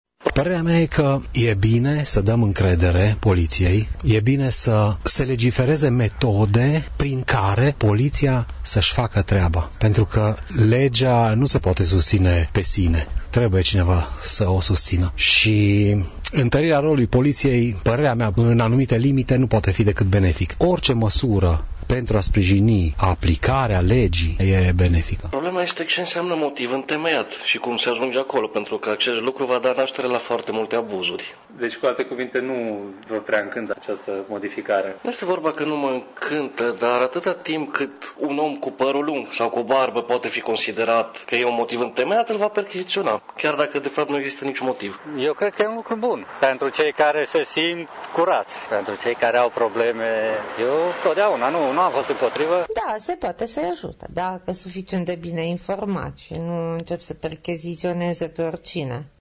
Părerile mureșenilor sunt împărțite, unii sunt de acord cu această măsură și o consideră benefică, în timp ce alții se tem că se vor comite abuzuri: